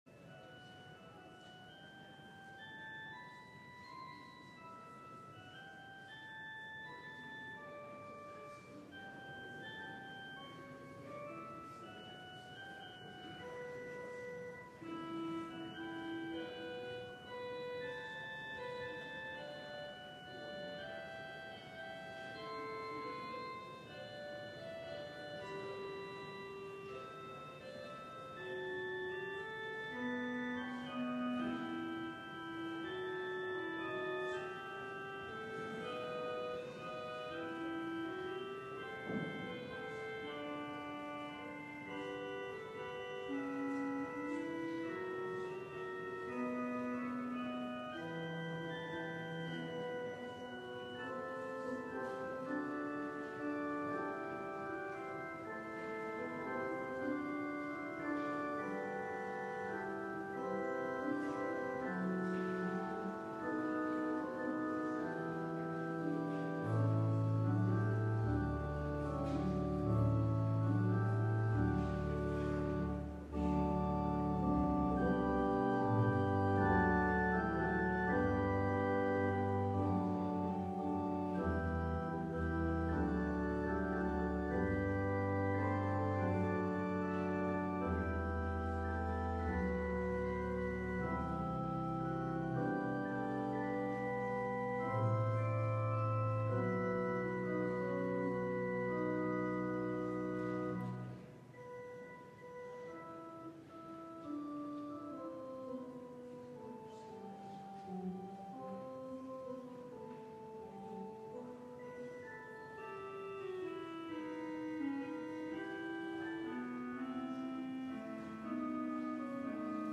LIVE Worship Service - Living By Faith
Congregational singing—of both traditional hymns and newer ones—is typically supported by our pipe organ.